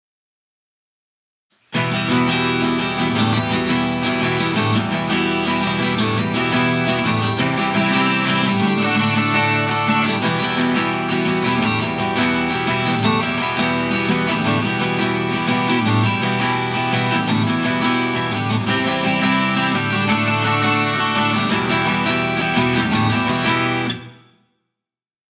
Сведение акустики